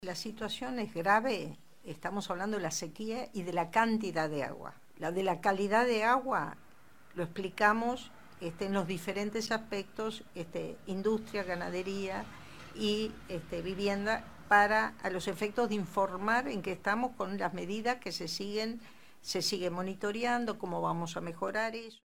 Eneida de León en conferencia